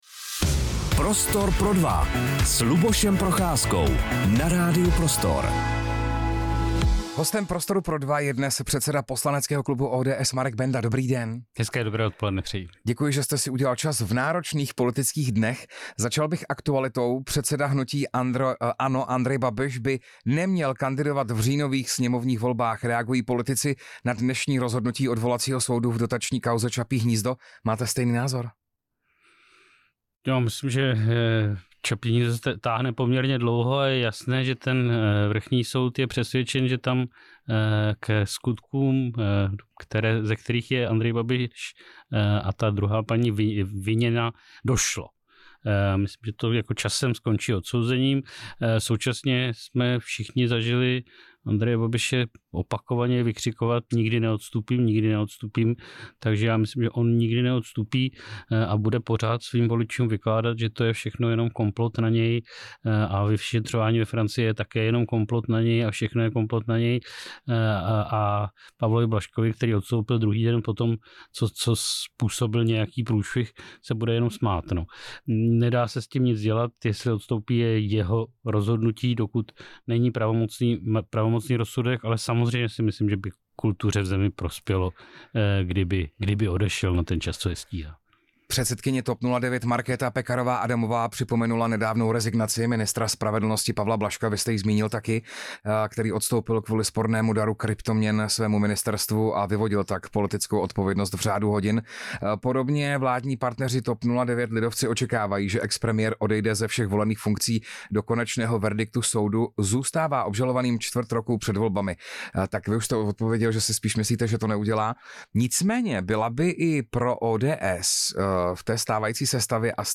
Rozhovor s předsedou poslaneckého klubu ODS Markem Bendou | Radio Prostor